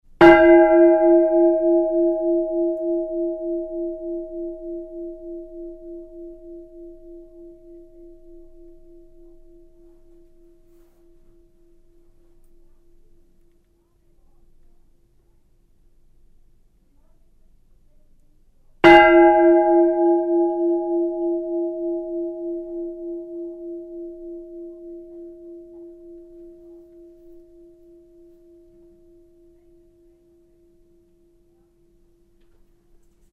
Schlagton Klanganalyse
schlagton-loetscherglocke-1483-web.mp3